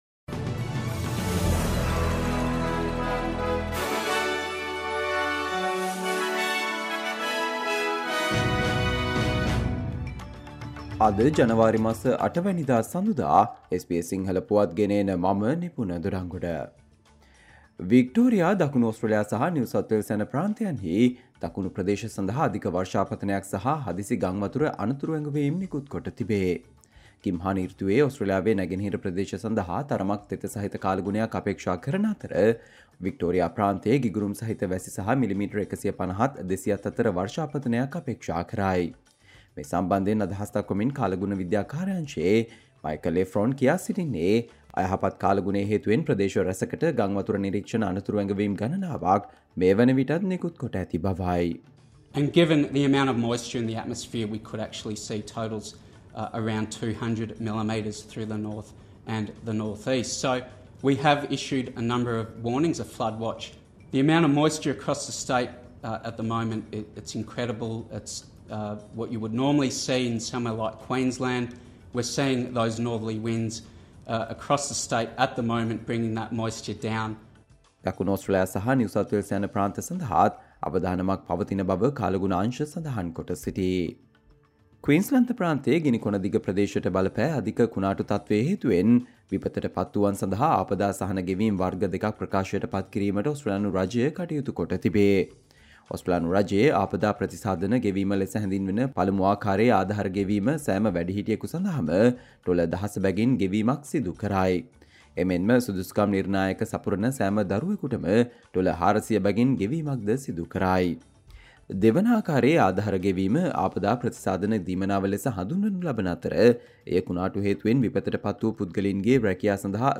Australia news in Sinhala, foreign and sports news in brief - listen, Monday 08 January 2024 SBS Sinhala Radio News Flash